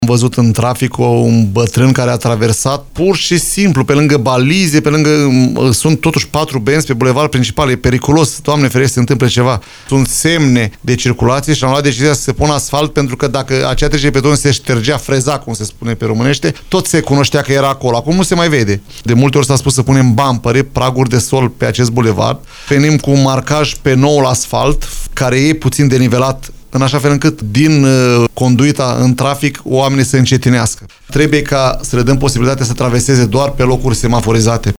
Viceprimarul LUCIAN HARȘOVSCHI a declarat postului nostru de radio că, în unele zone, va fi turnat asfalt cu denivelări tocmai pentru a diminua pericolul accidentelor de circulație.